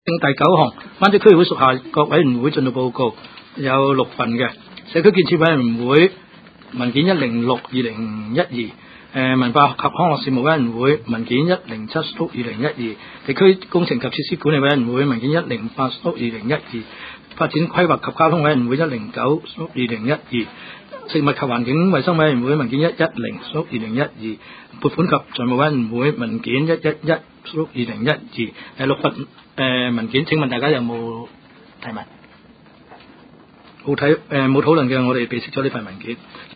区议会大会的录音记录
湾仔区议会第七次会议